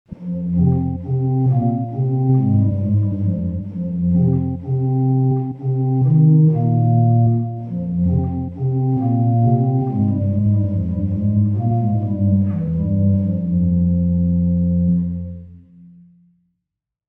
Portatyw
Zbudowany był z ołowianych piszczałek, każdej z nich odpowiadał klawisz na klawiaturze.
Dźwięki instrumentów są brzmieniem orientacyjnym, wygenerowanym w programach:
Kontakt Native Instruments (głównie Factory Library oraz inne biblioteki) oraz Garritan (Aria Player).
Portatyw.mp3